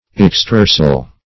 extrorsal - definition of extrorsal - synonyms, pronunciation, spelling from Free Dictionary Search Result for " extrorsal" : The Collaborative International Dictionary of English v.0.48: Extrorsal \Ex*tror"sal\, a. (Bot.)